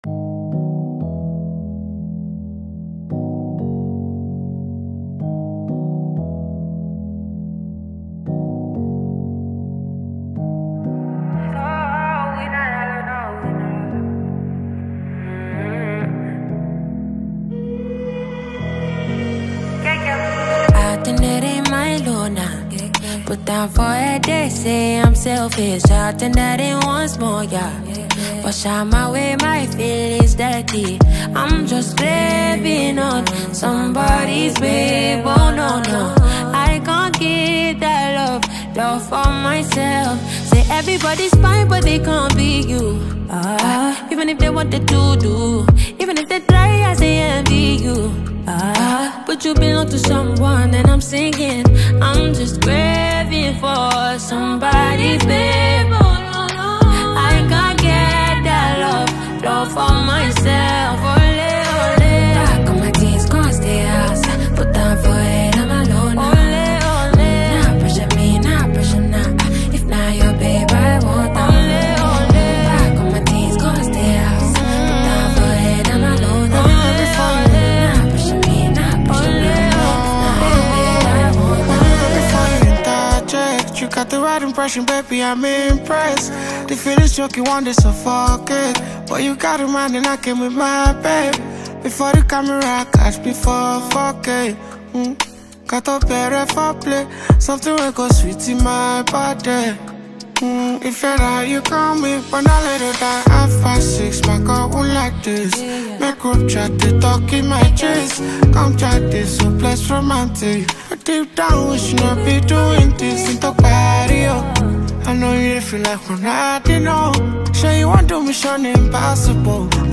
With its captivating melody and infectious beats